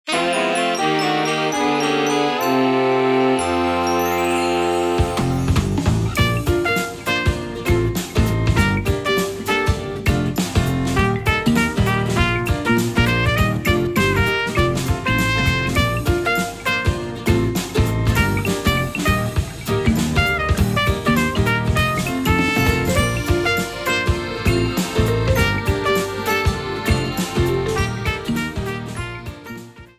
The title screen music
Shortened, applied fade-out and converted to oga